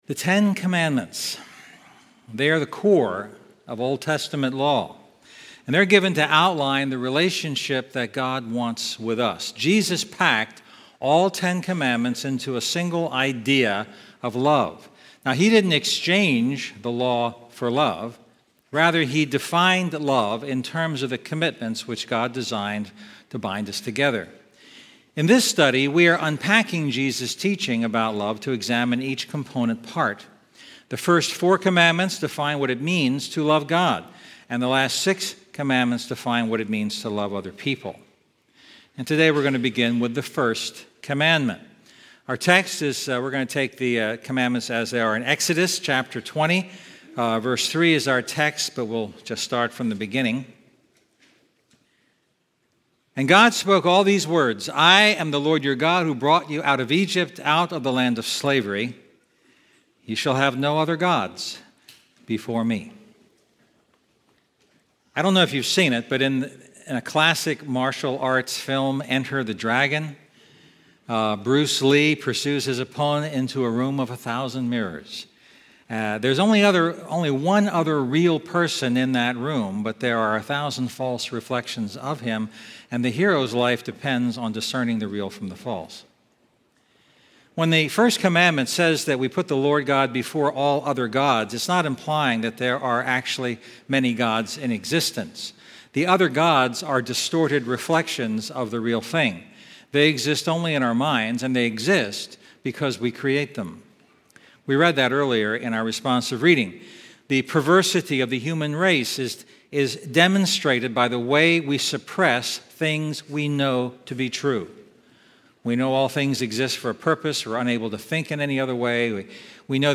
Sermons – GrowthGround